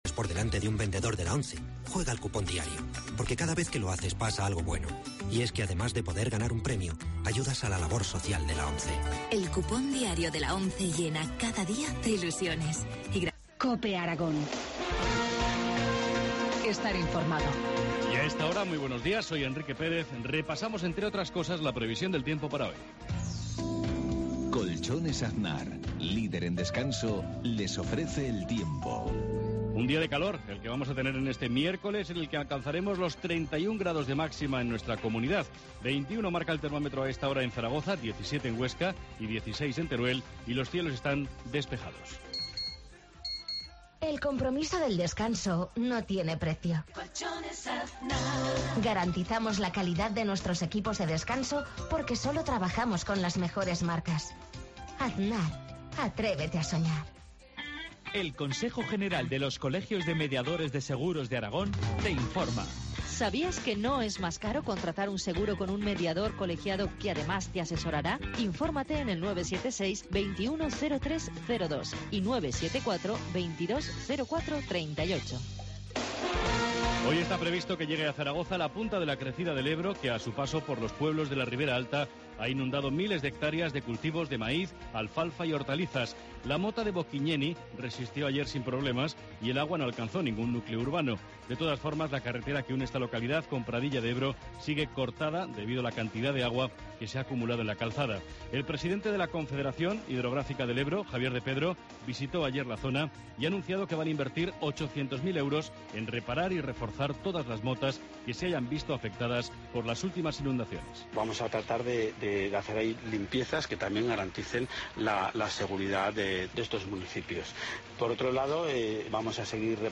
Informativo matinal, miércoles 12 de junio, 7.53 horas